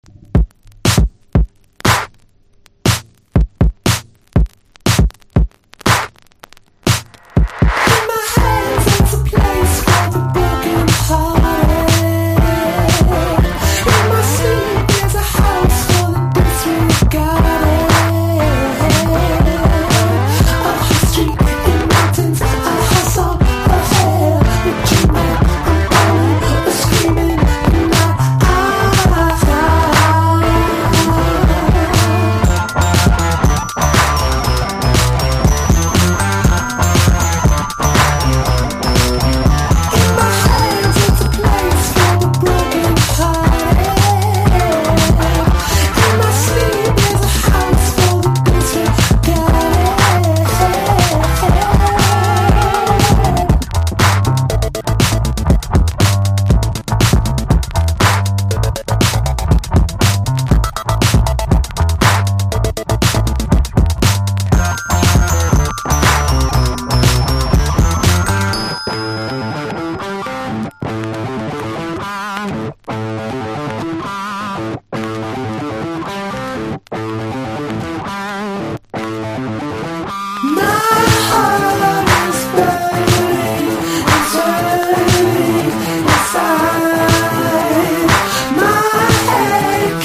INDIE DANCE
エレクトロの向こう側を見据えたソウルフルなシンセファンクポップサウンド！！